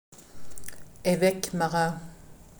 pronunciation)